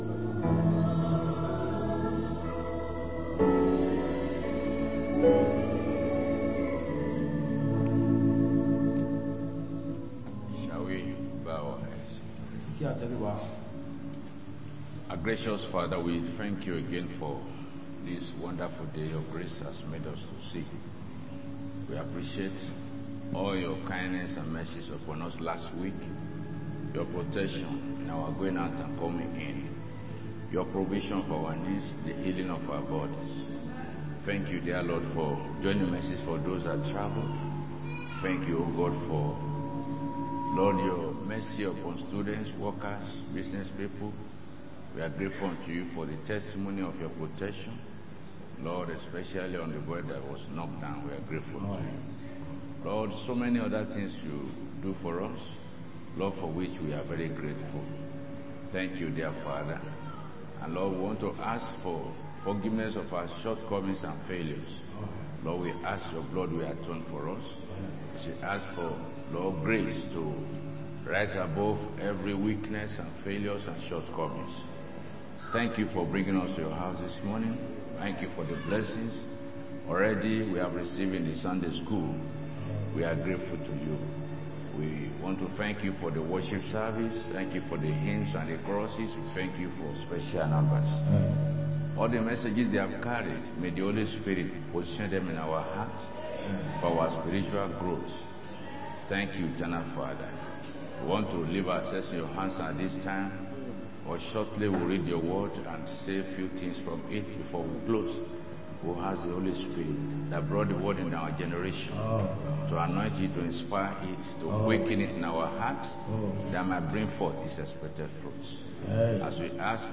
Sunday Main Service 19-10-25